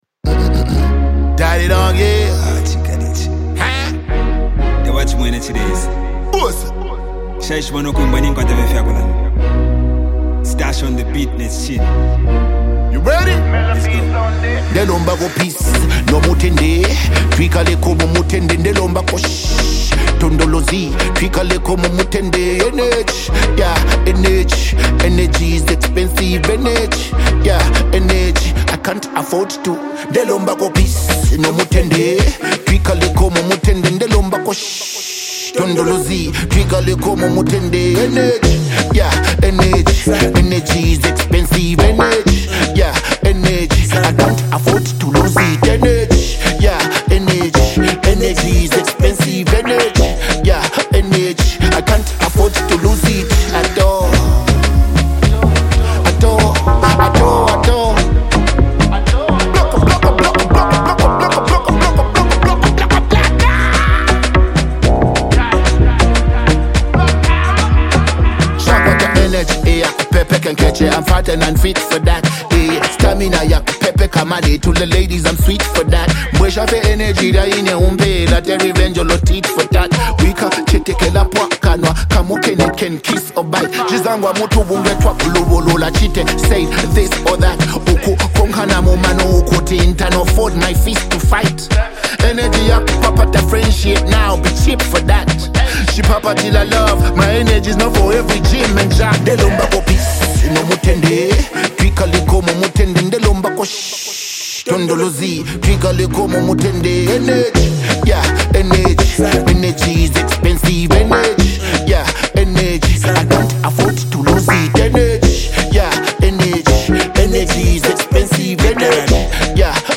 combining catchy hooks with vibrant production.